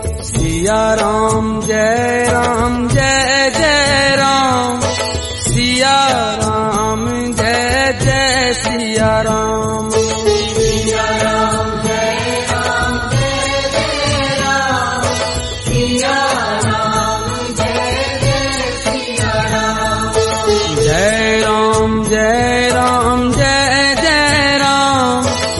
divine melody
spiritual tune
devotional